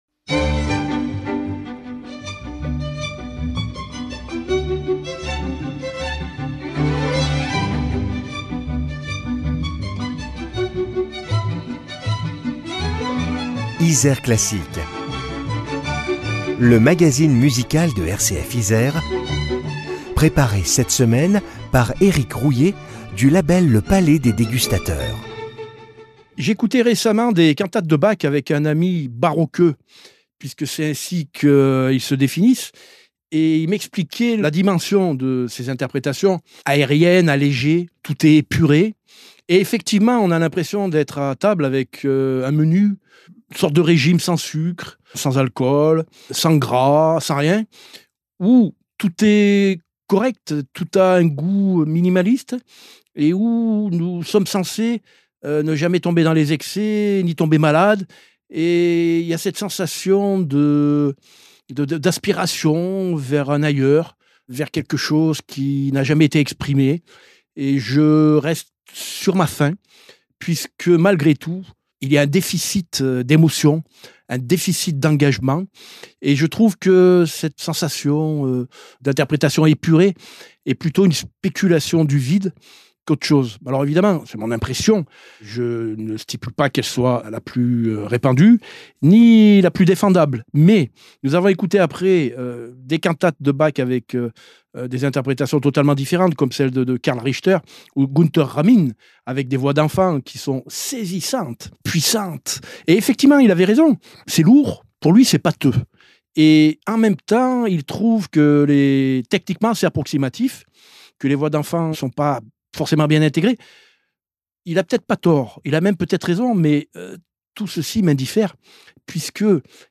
Eglise de La Madeleine à Paris le 25 juin 1985 Choeurs et Ensemble instrumental de La Madeleine
soprano
alto
ténor
basse) Orgue